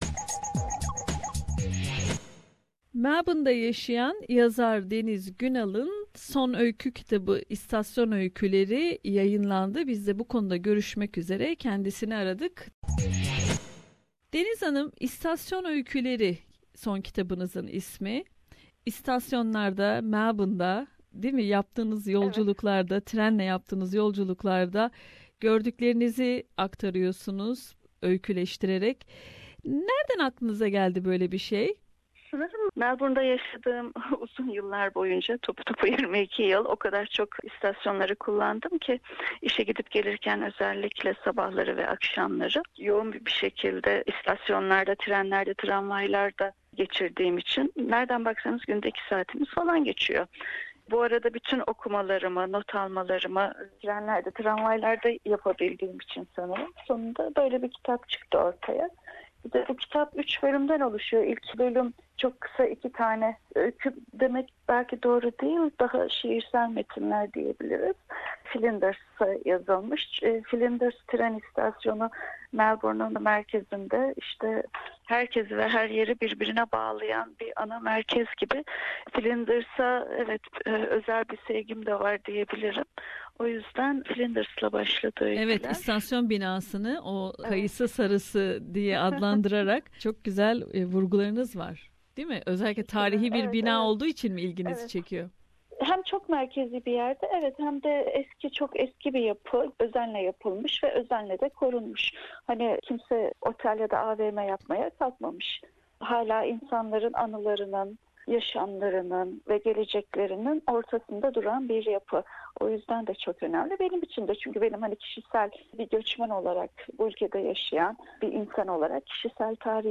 Söyleşiyi dinlemek için resmin üzerini tıklayınız.